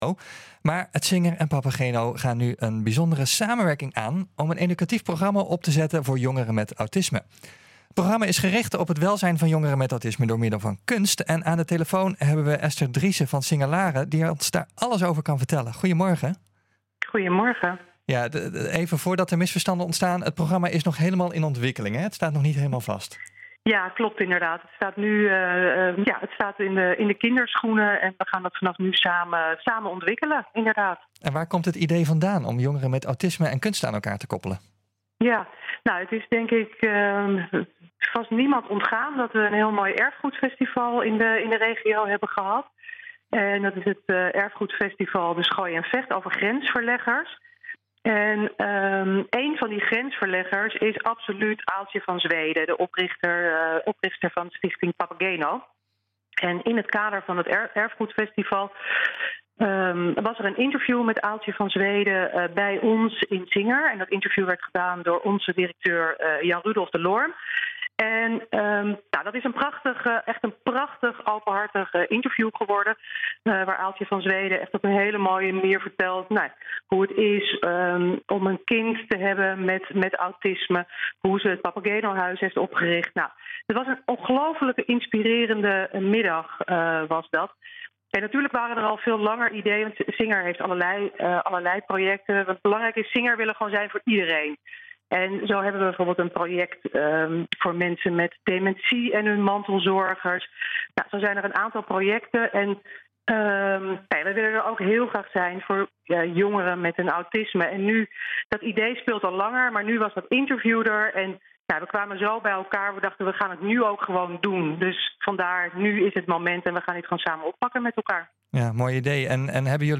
Singer Laren en Stichting Papageno gaan een samenwerking aan om een educatief programma op te zetten voor jongeren met autisme. Het programma is gericht op het welzijn van jongeren met autisme door middel van kunst. Aan de telefoon